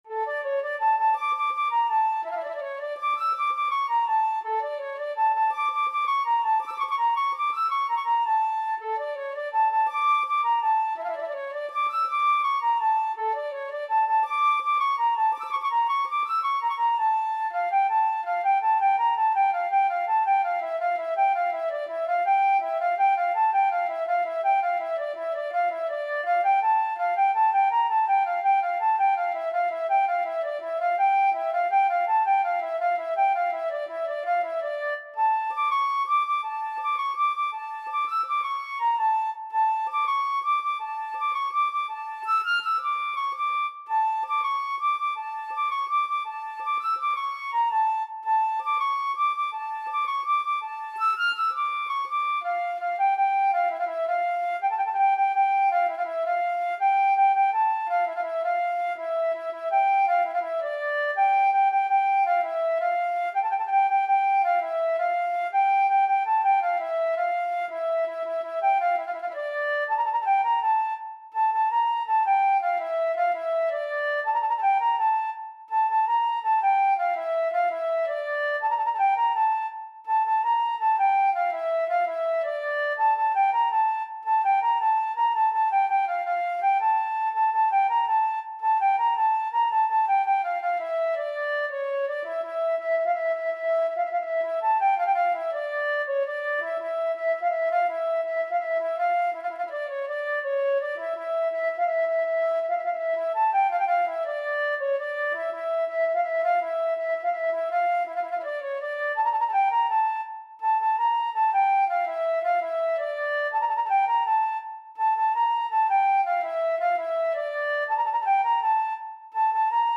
تنظیم شده برای فلوت